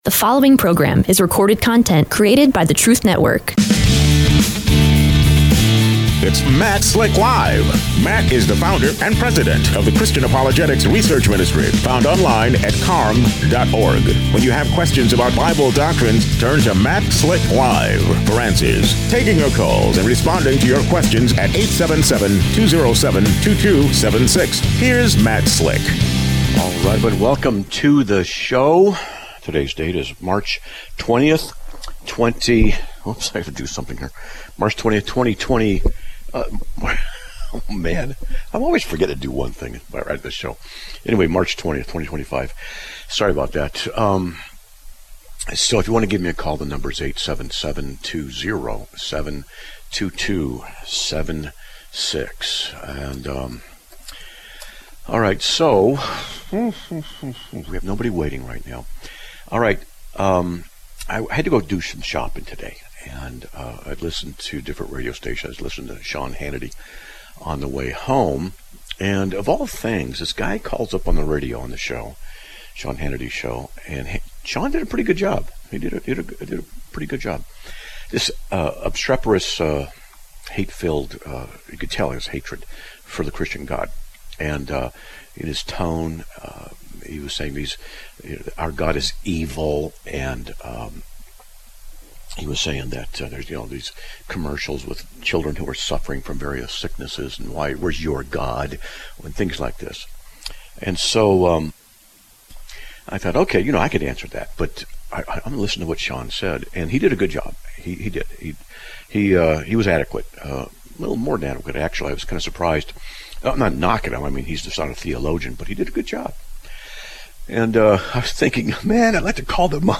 A Caller asks if The Scriptures Justify Capital Punishment